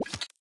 Media:bo_mine_land.wav 技能音效 super 高手形态扔出地雷音效
Bo_mine_land.wav